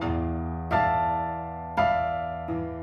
GS_Piano_85-E2.wav